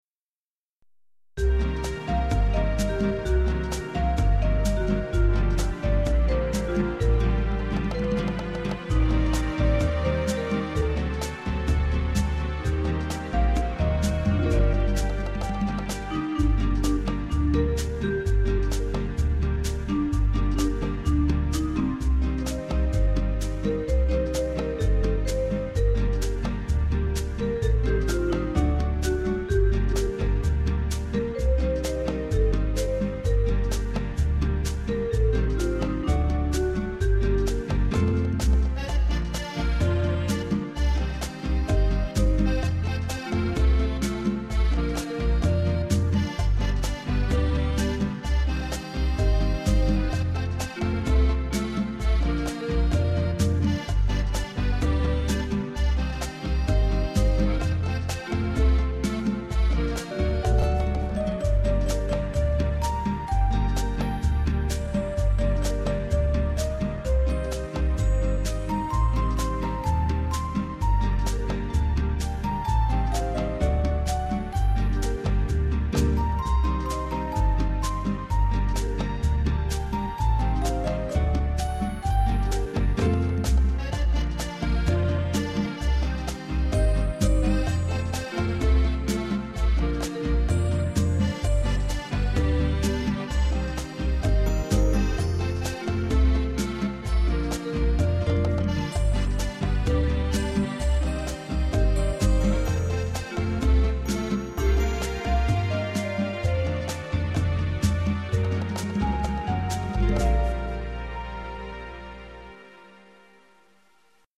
Детская песня.